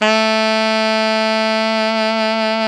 Index of /90_sSampleCDs/Roland LCDP07 Super Sax/SAX_Alto Tube/SAX_Alto ff Tube
SAX ALTOFF03.wav